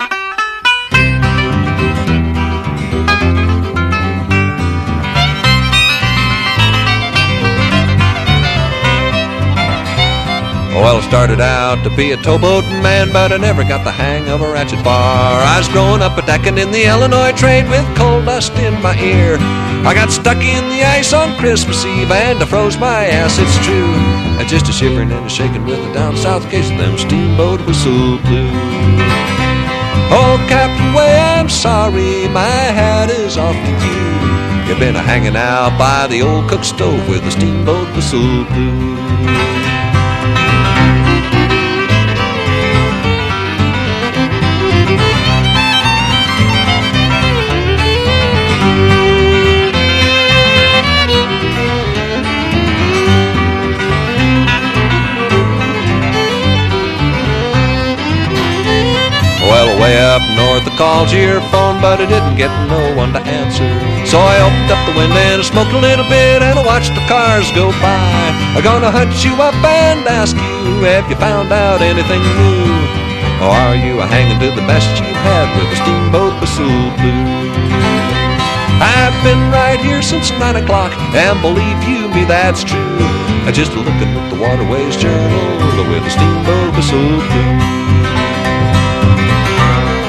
UKULELE SWING
12人編成で送るウクレレ・オーケストラ！